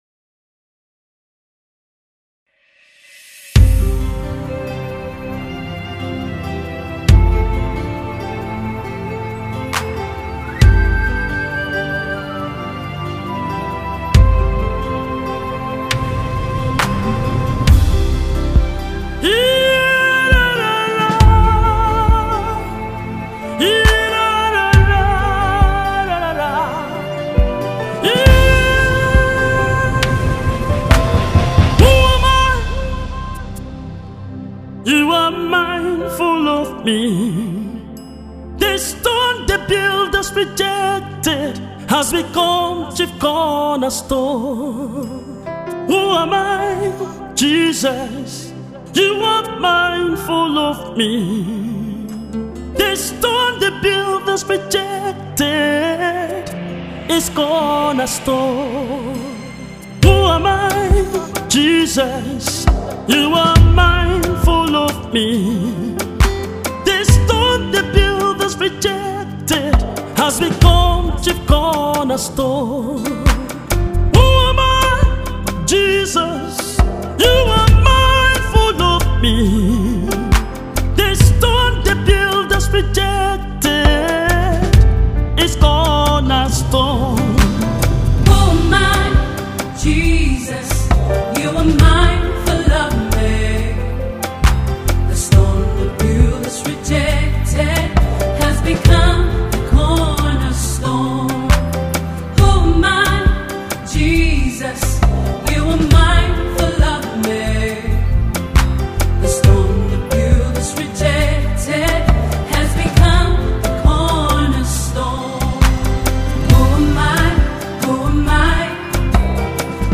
Gospel Singer, vocalist
worship single
Gospel Music